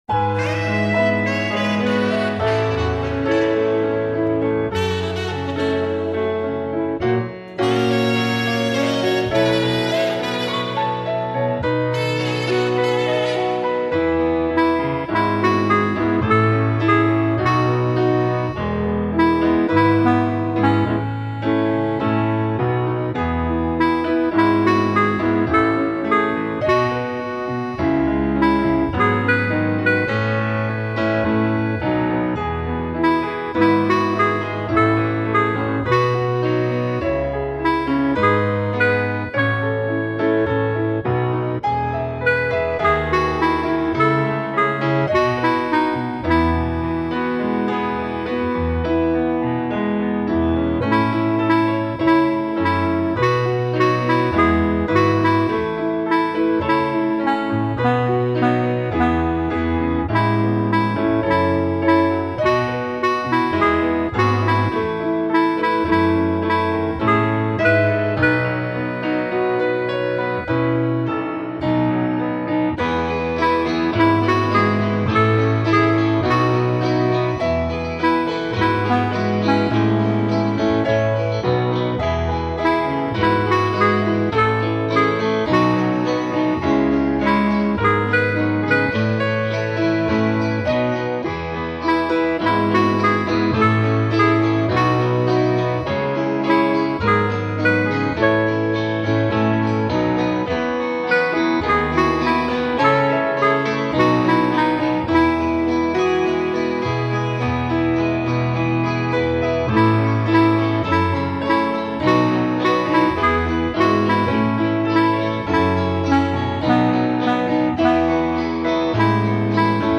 My backing keeps it simple.